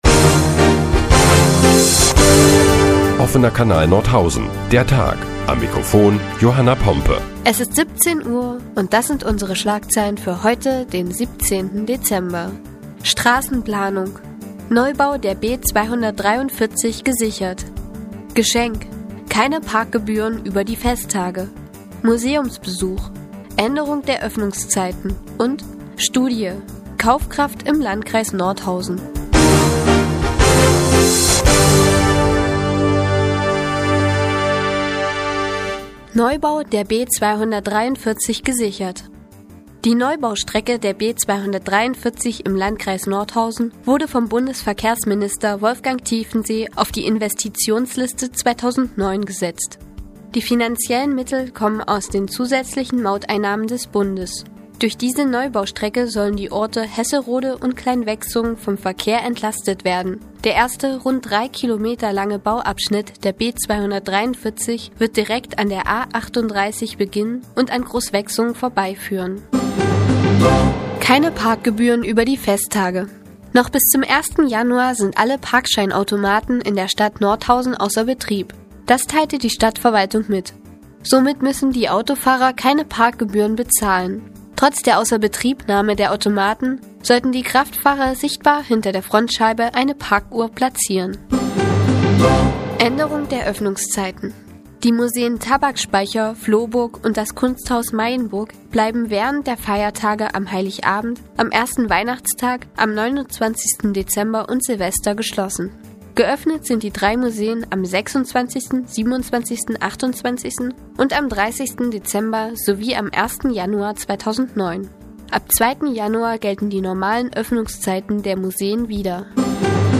Die tägliche Nachrichtensendung des OKN ist nun auch in der nnz zu hören. Heute geht es unter anderem um den Neubau der B 243 und die Kaufkraft der Nordhäuser Bürger.